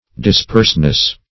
Disperseness \Dis*perse"ness\, n.
disperseness.mp3